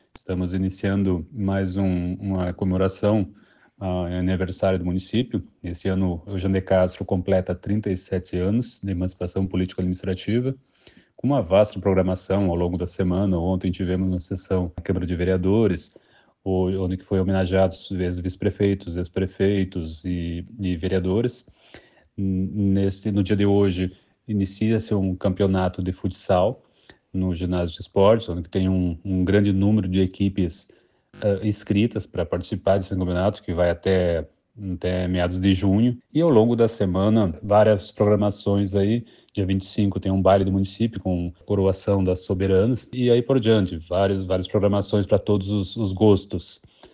Abaixo, manifestação do prefeito, Fernando Machado: